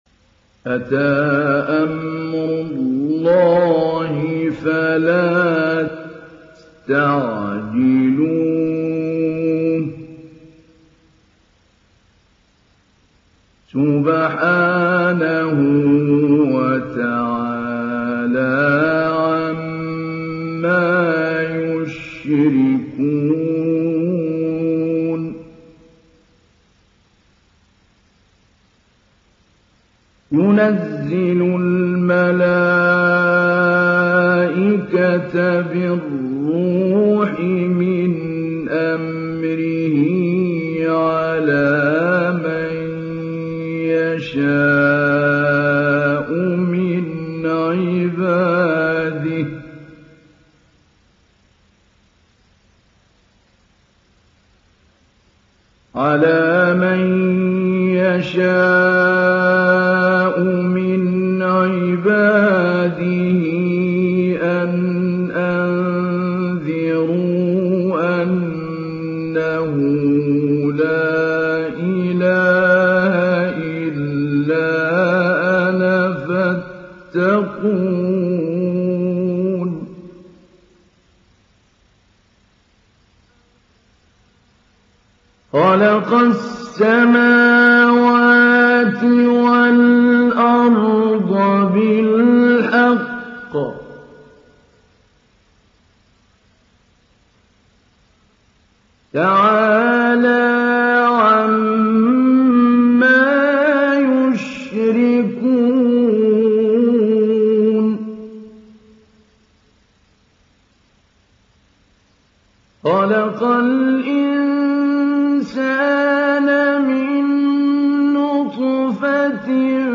Download Surah An Nahl Mahmoud Ali Albanna Mujawwad